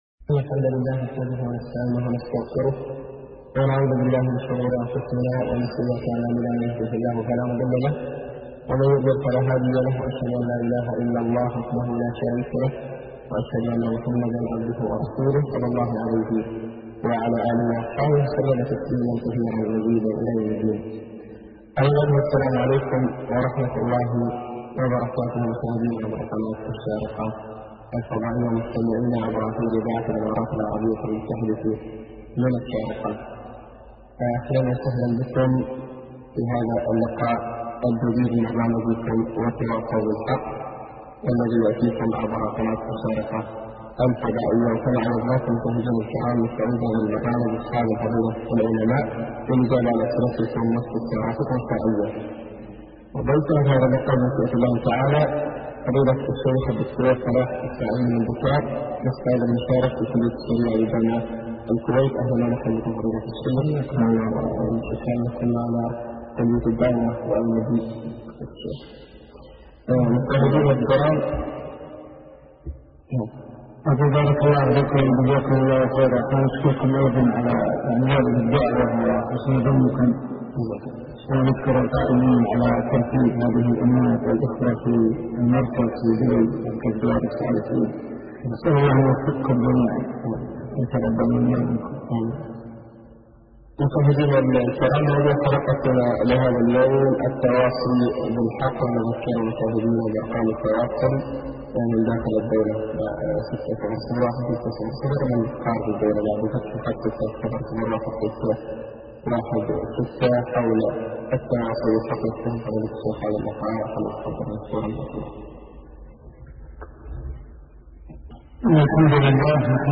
لقاء في قناة الشارقة